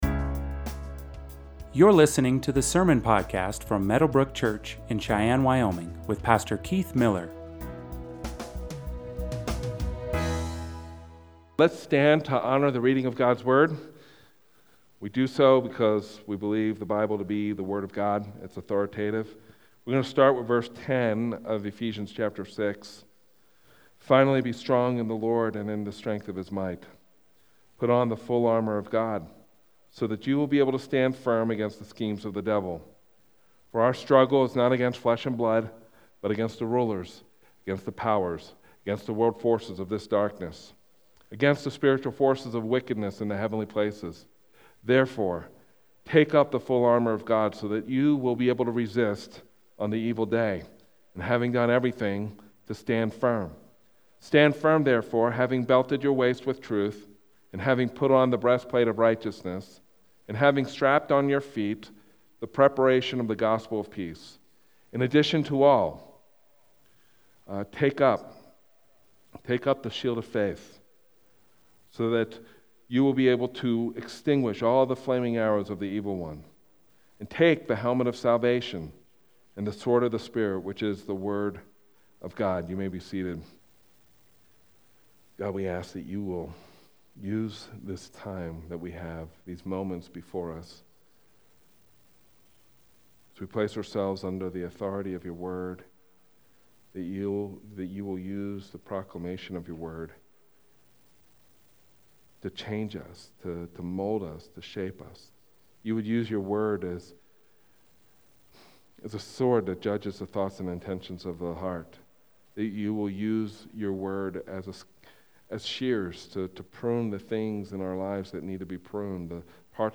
Sermons | Meadowbrooke Church